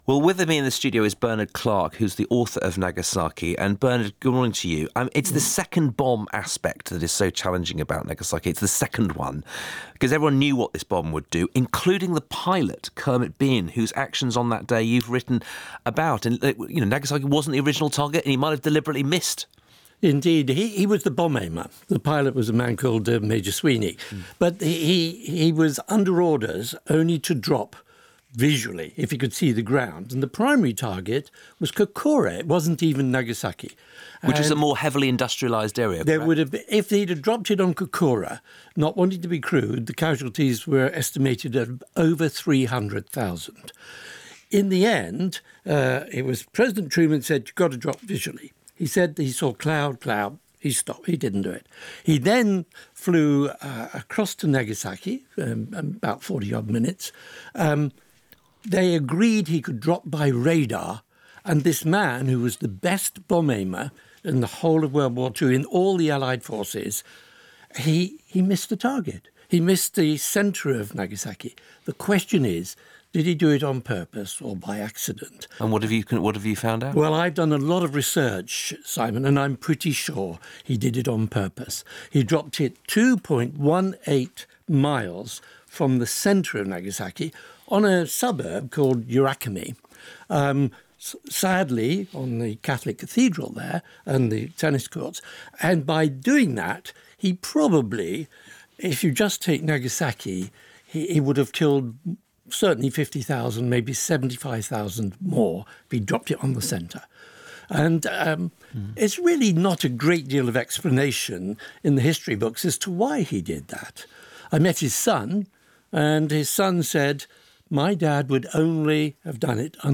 Interview - Today Programme - Radio 4